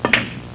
Playing Pool Sound Effect
playing-pool.mp3